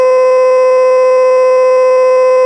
机器人声乐 " 合成歌声音符C 变奏5
Tag: 歌唱 语音 合成器 数字 声码器 笔记 puppycat 机器人 电子 合成 自动调谐 C